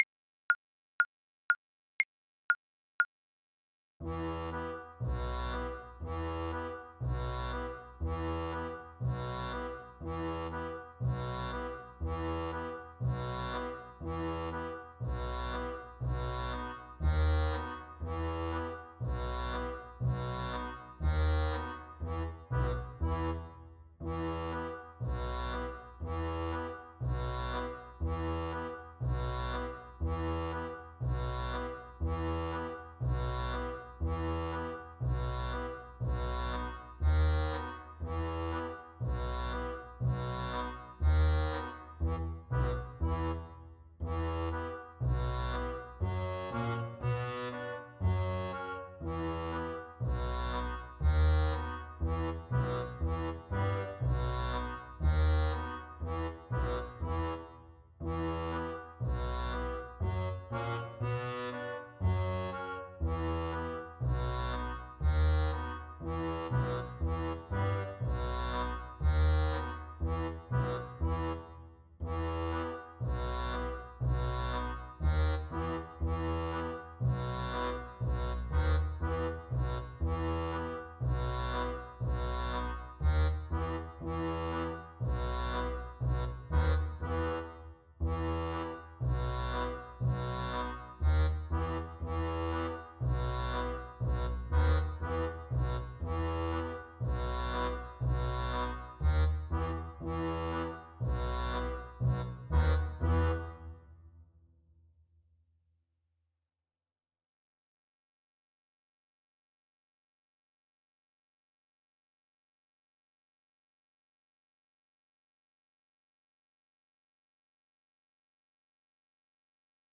Play (or use space bar on your keyboard) Pause Music Playalong - Piano Accompaniment transpose reset tempo print settings full screen
Violin
Odessa Bulgarish is a traditional klezmer piece originating in the Ukraine.
4/4 (View more 4/4 Music)
B minor (Sounding Pitch) (View more B minor Music for Violin )
Klezmer Violin